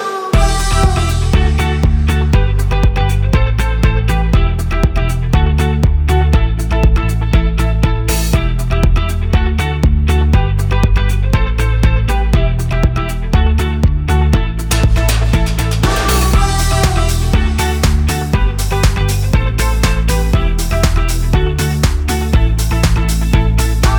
no Backing Vocals Dance 3:41 Buy £1.50